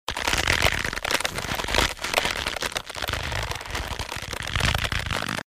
target_stretching_aim_loop.ogg